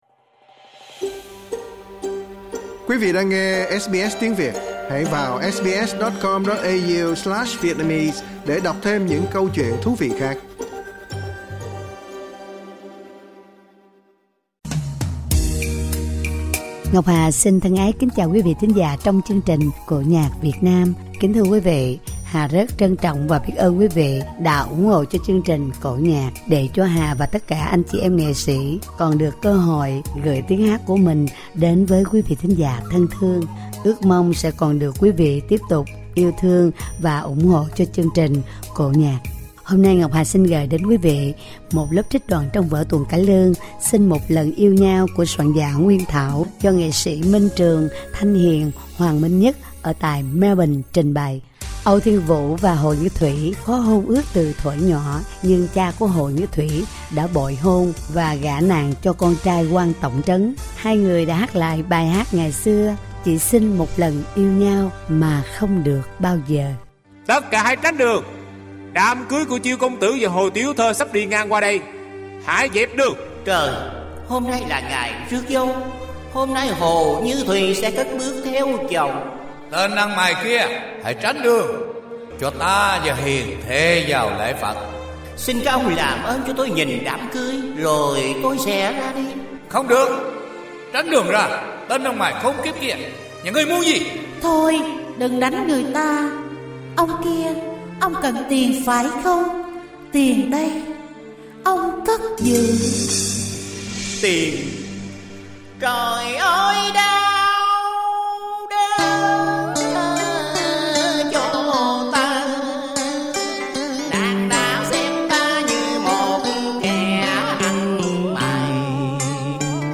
trích đoạn trong vở cải lương